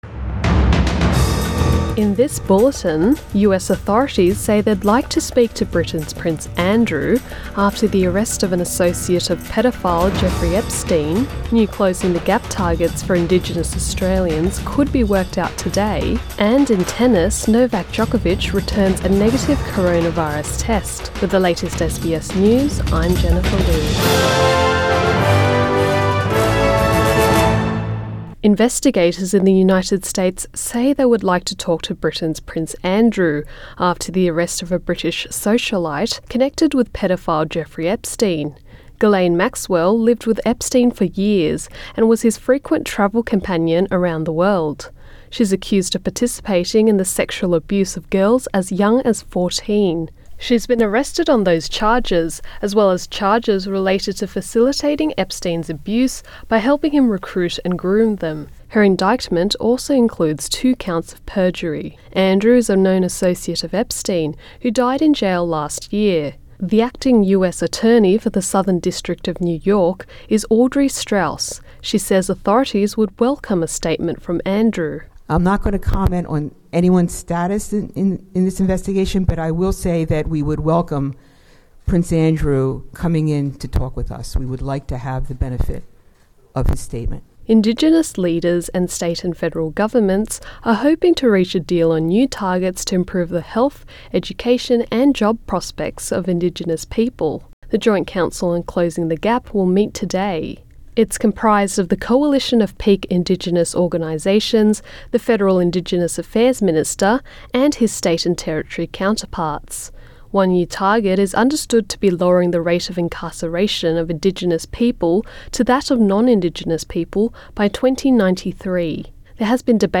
AM bulletin 3 July 2020